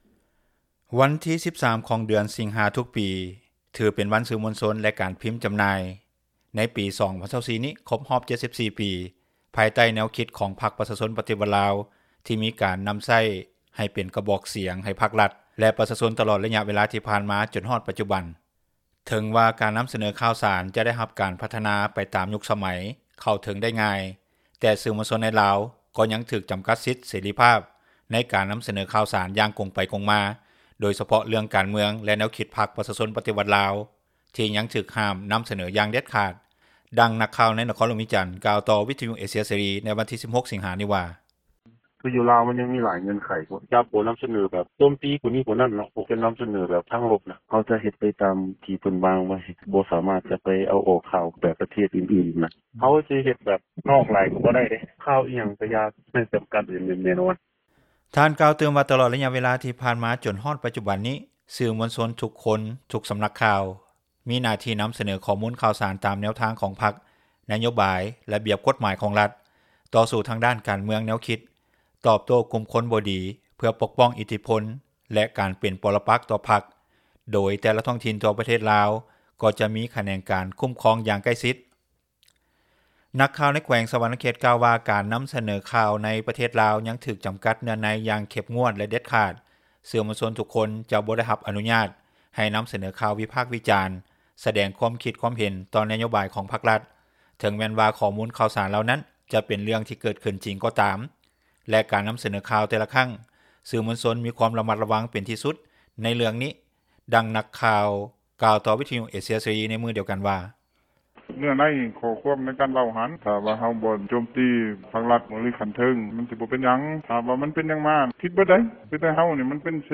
ດັ່ງນັກຂ່າວໃນນະຄອນຫຼວງວຽງຈັນກ່່າວຕໍ່ວິທຍຸເອເຊັຽເສຣີໃນວັນທີ 16 ສິງຫາ ນີ້ວ່າ.